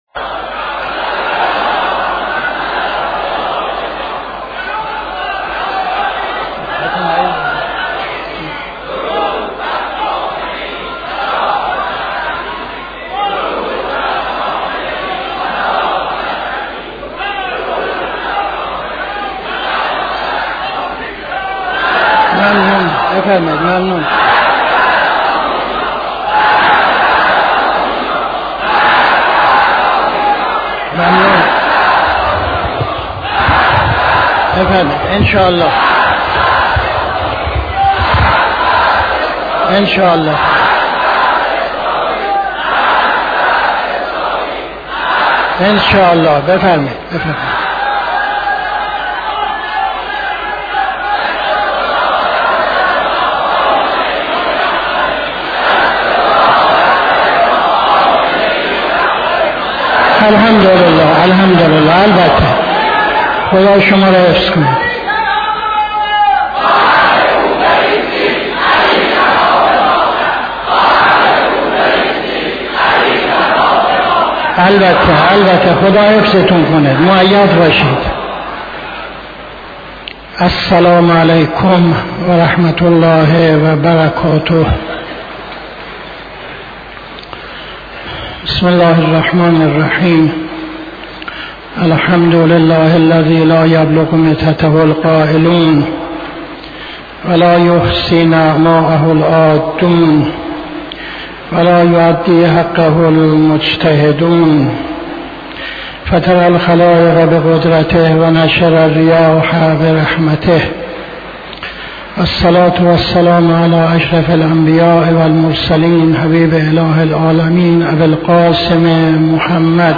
خطبه اول نماز جمعه 05-06-78